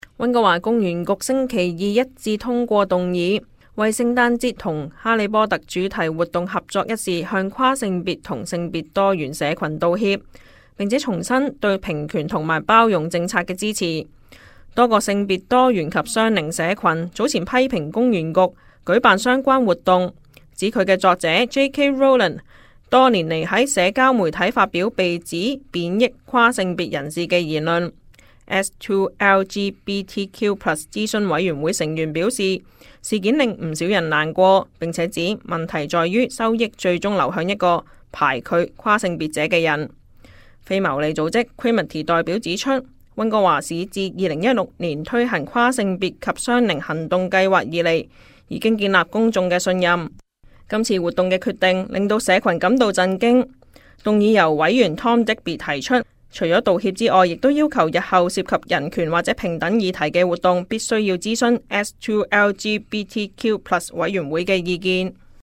Local News 本地新聞